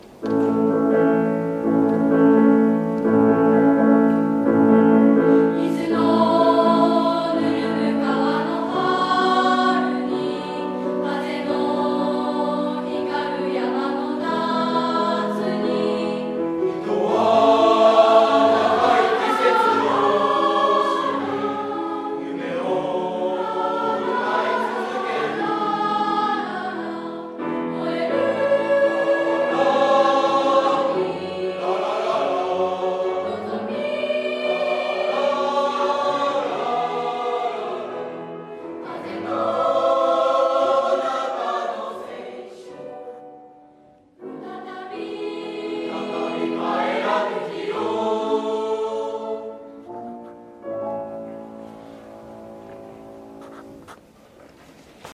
合唱コンクール3-1課題曲 「生徒が作るホームページ」 作成 3年1組書記委員